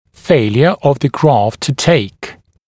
[‘feɪljə əv ðə grɑːft tə teɪk][‘фэйлйэ ов зэ гра:фт ту тэйк]неприживление пересаженной костной или иной ткани